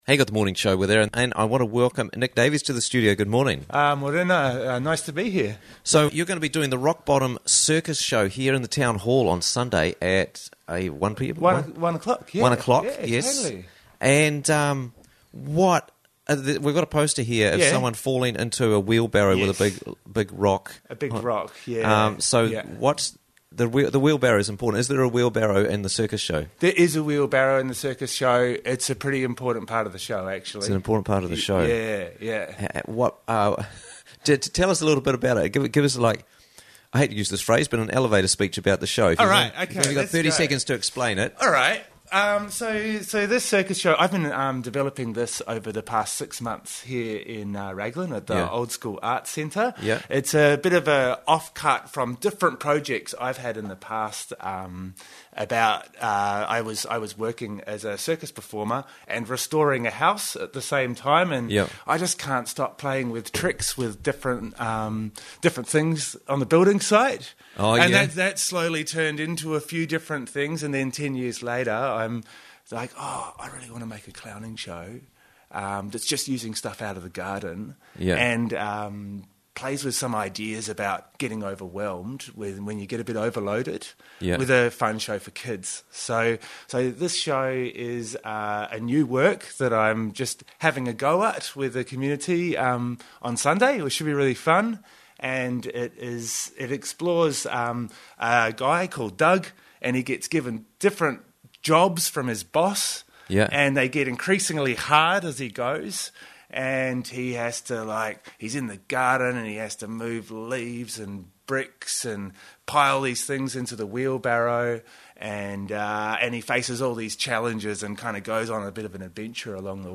Quickly and easily listen to Interviews from the Raglan Morning Show for free!